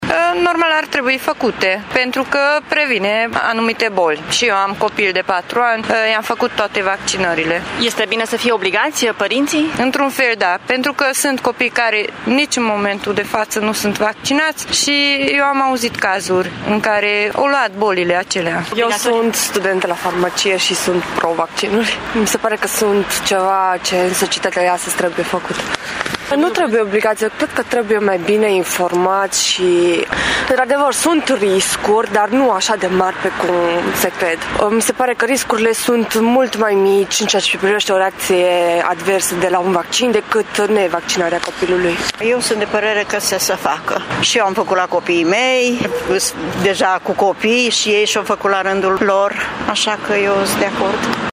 Târgumureșenii cred că este bine ca părinții să-și vaccineze copiii iar pentru asta e nevoie de o informare mai bună, și nu de constrângeri: